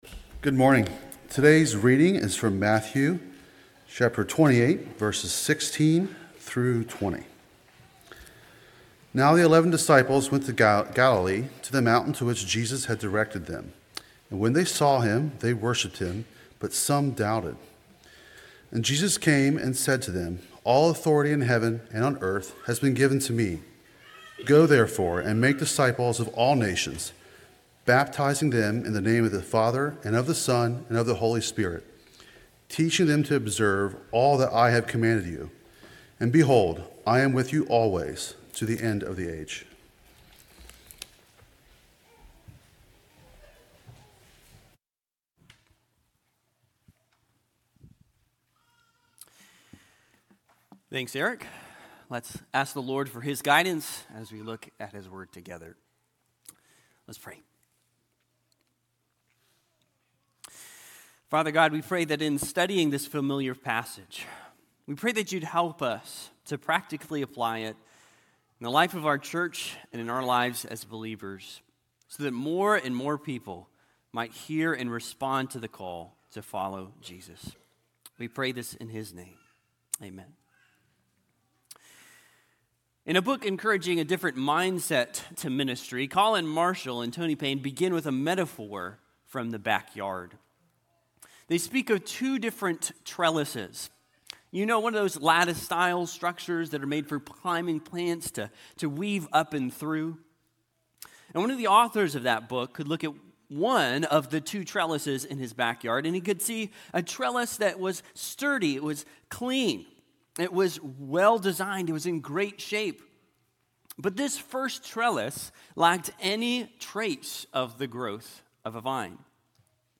sermon4.12.26.mp3